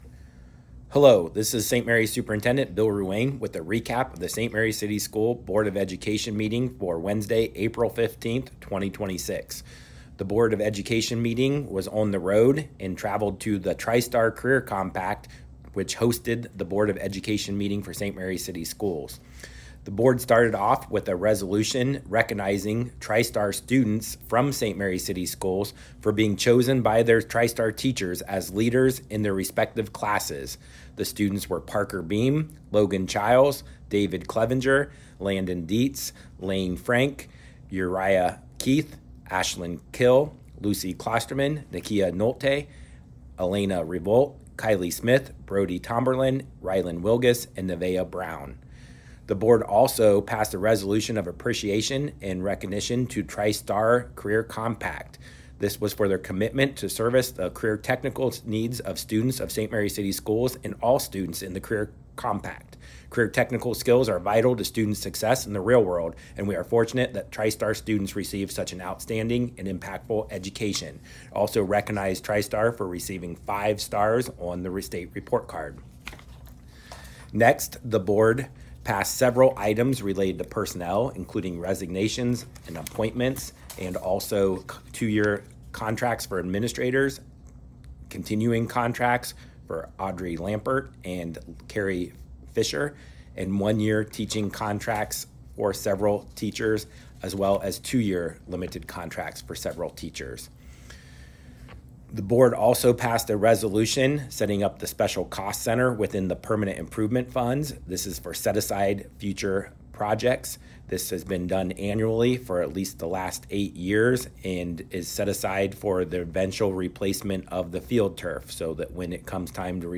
Local News